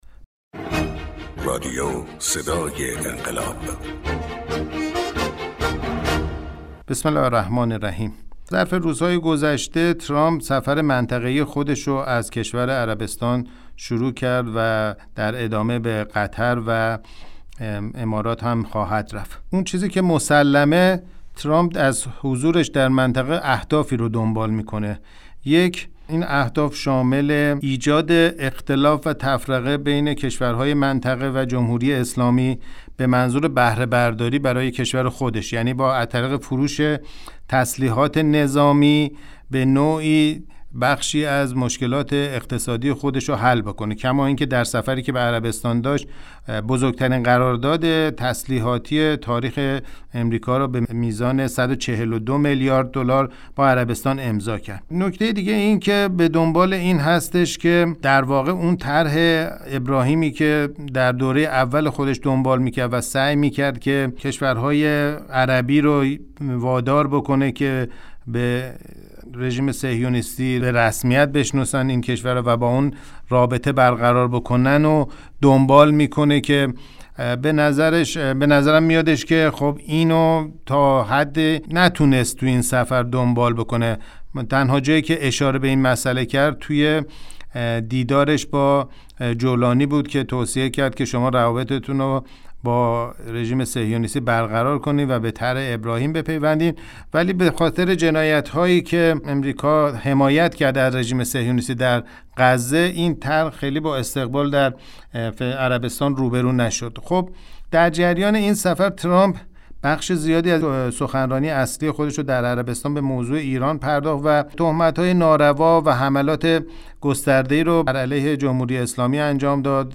کارشناس مسائل سیاسی